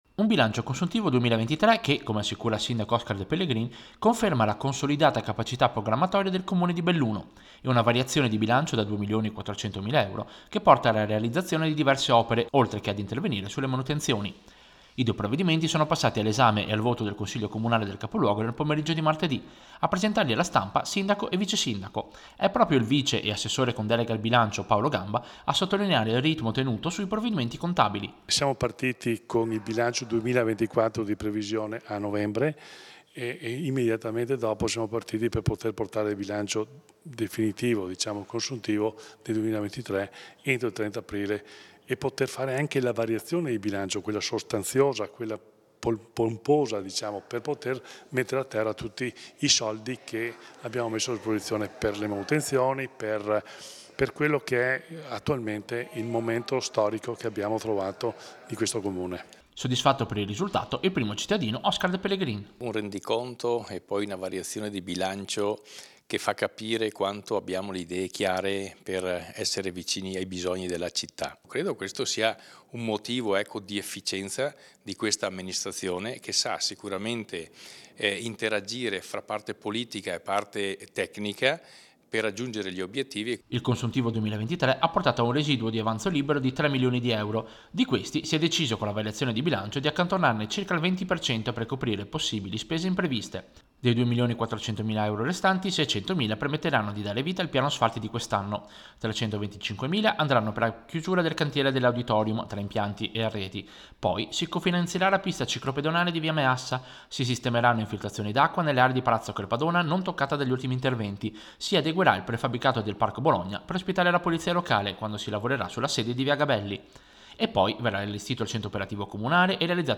Radio-Piu-Servizio-Consuntivo-e-variazione-2024-Belluno.mp3